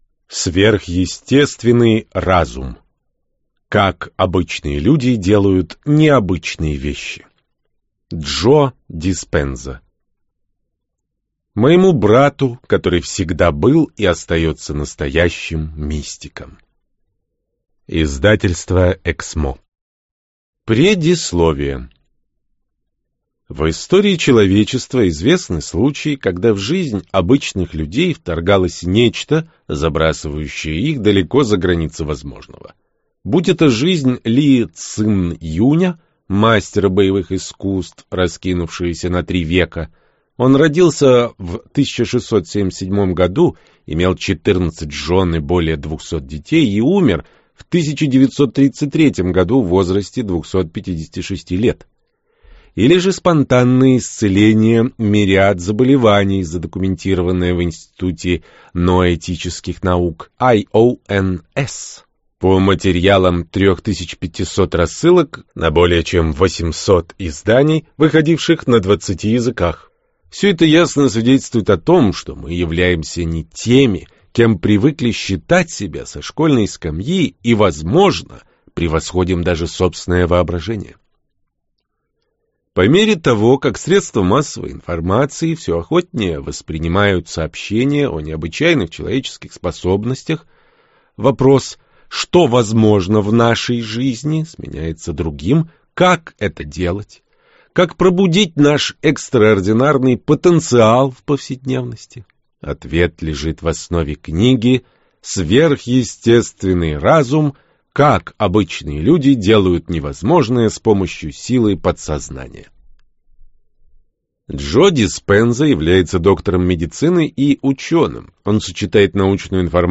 Аудиокнига Сверхъестественный разум. Как обычные люди делают невозможное с помощью силы подсознания - купить, скачать и слушать онлайн | КнигоПоиск